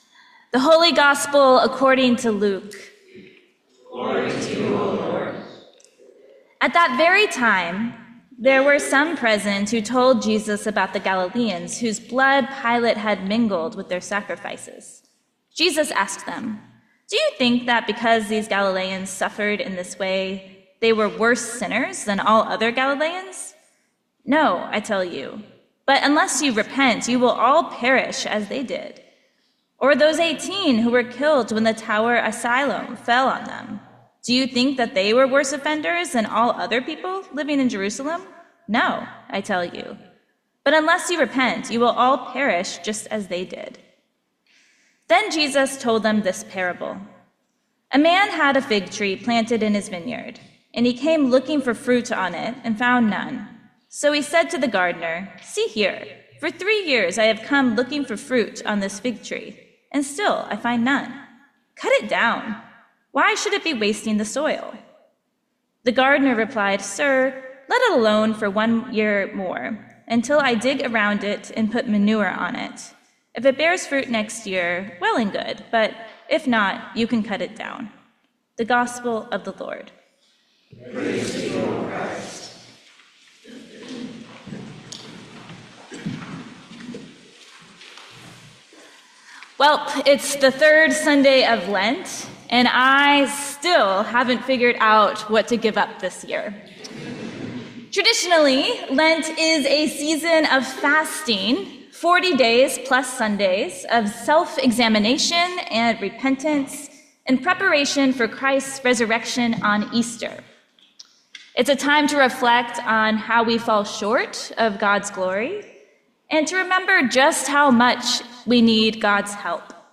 Sermon for the Third Sunday in Lent 2025